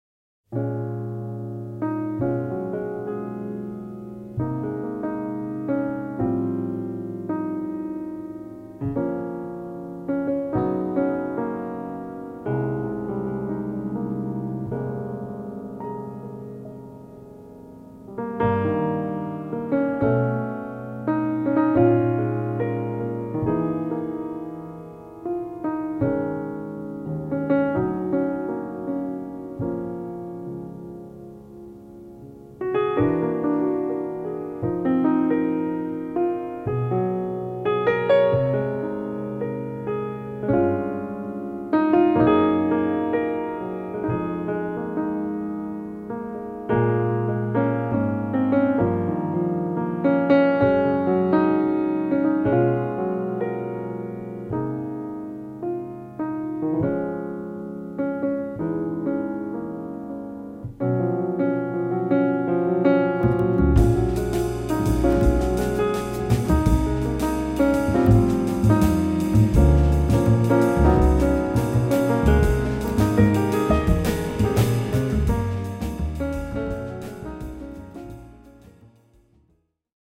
少しラフなサウンドを残したかったのであまり作り込まない、というアイディアは良い選択でした。